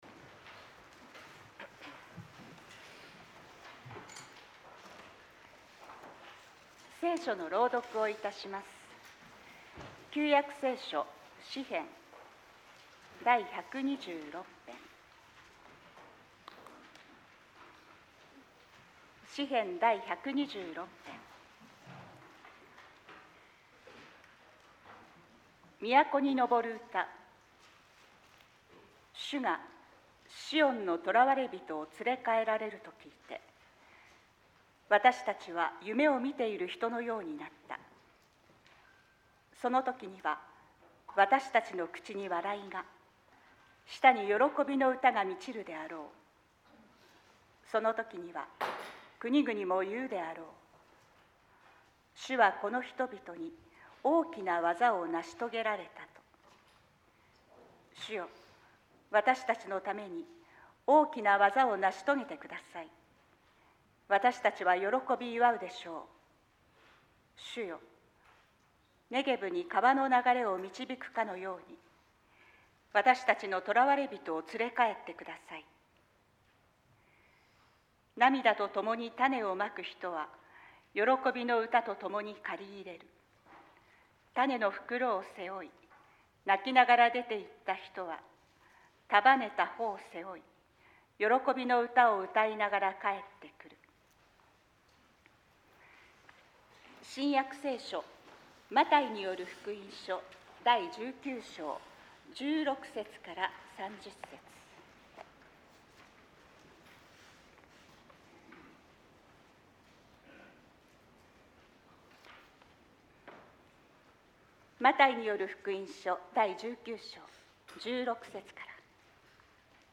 ２０２６年２月１５日 説教題「神は何でもできる」